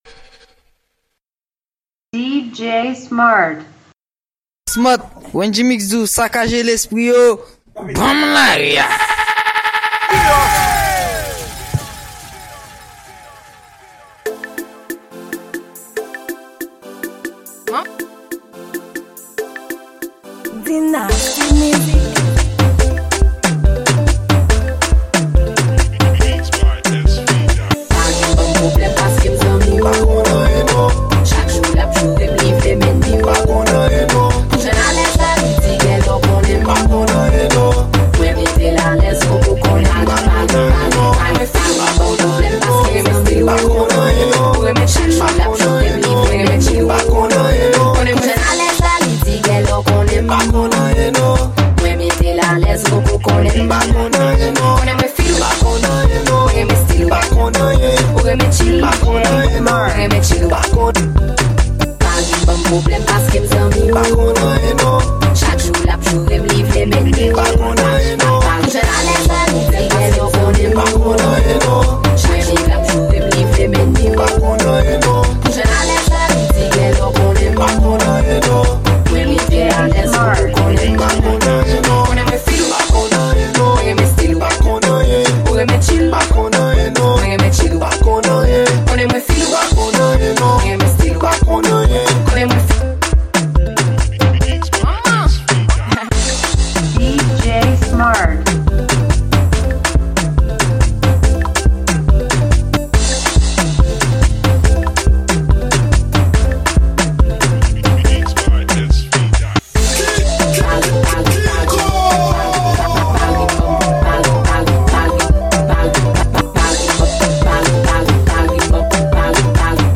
Genre: Mix.